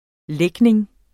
Udtale [ ˈlεgneŋ ]